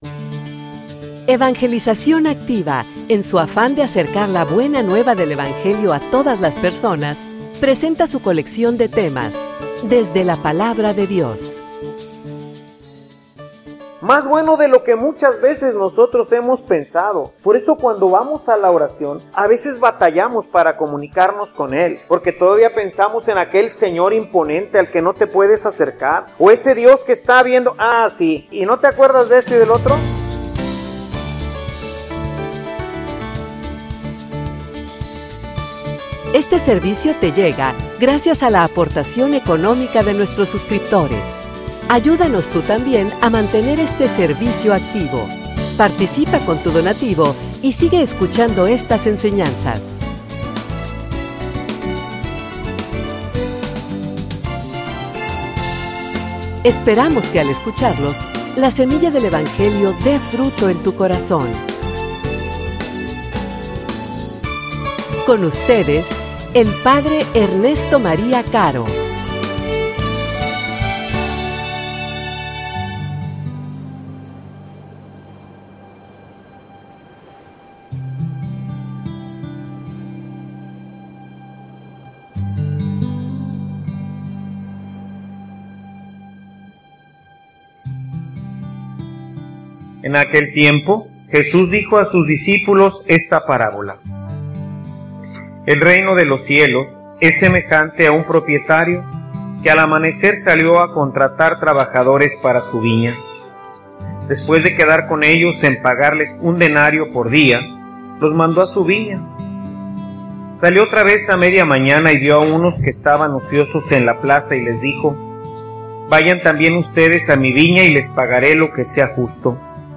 homilia_Dios_es_bueno.mp3